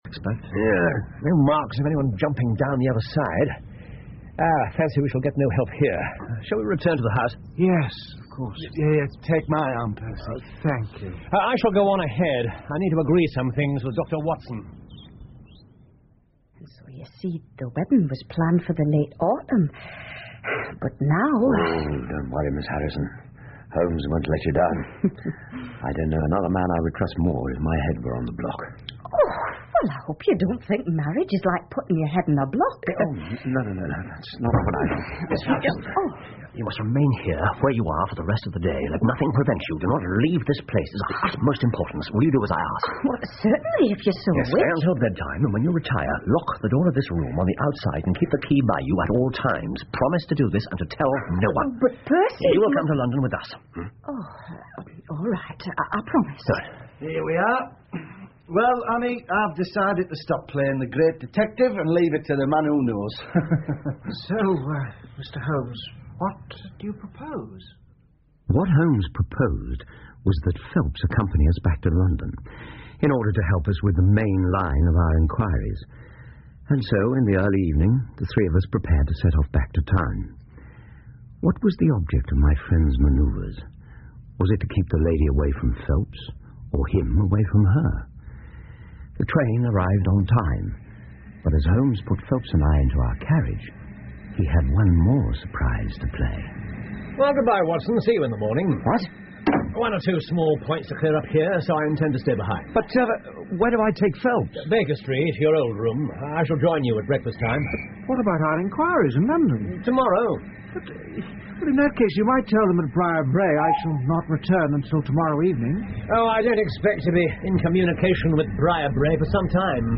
福尔摩斯广播剧 The Naval Treaty 7 听力文件下载—在线英语听力室